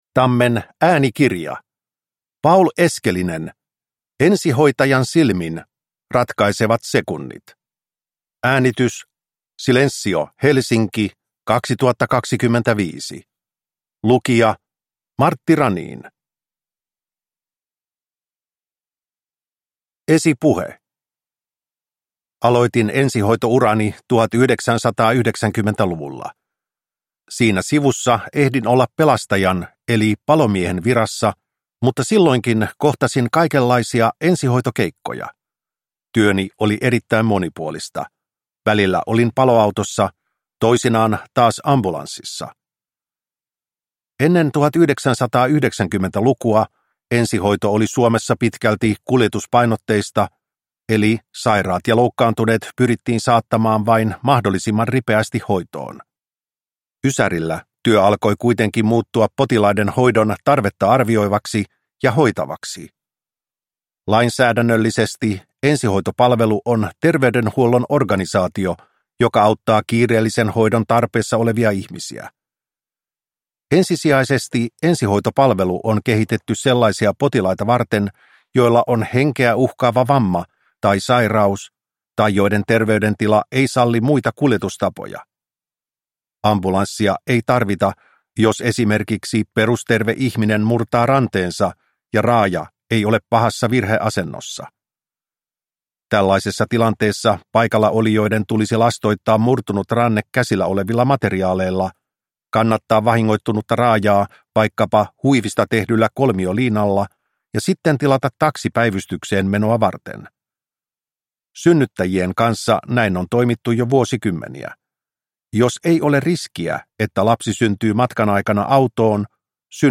Ensihoitajan silmin - Ratkaisevat sekunnit – Ljudbok